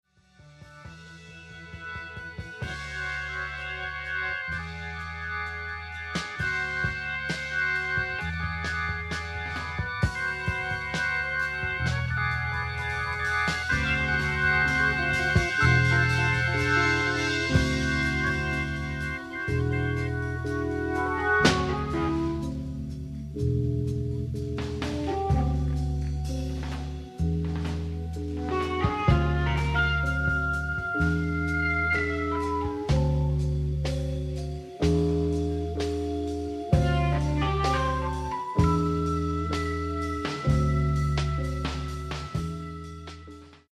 Drole de vibrato lent sur l'orgue, hum???